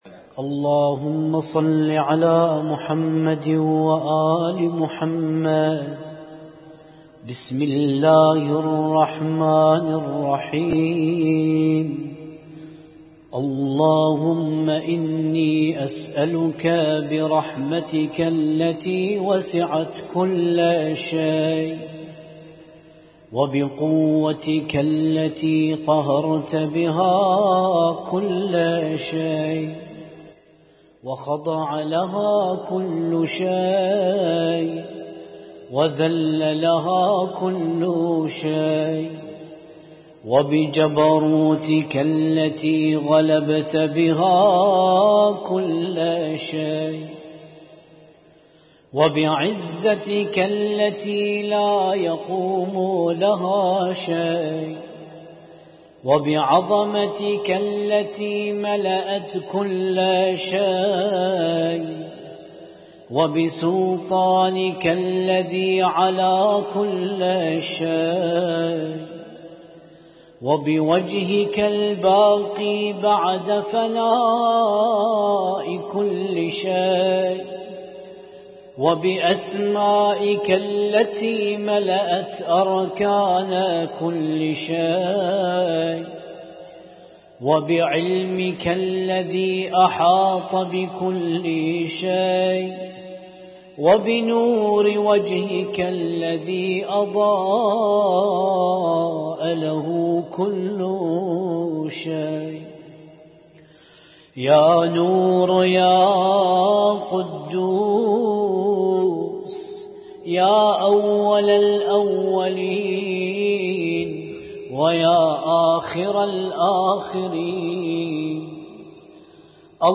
القارئ: الرادود
اسم التصنيف: المـكتبة الصــوتيه >> الادعية >> دعاء كميل